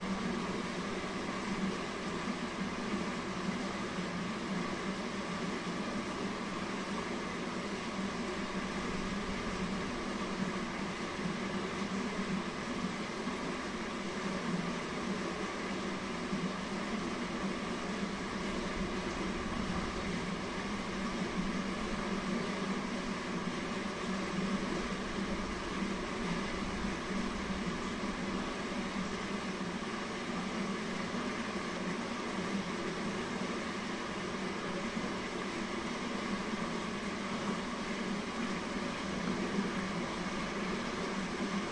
家居用品 " 融化的雪
描述：从熔化的雪滴下在排水管的水。
Tag: 滴落 熔化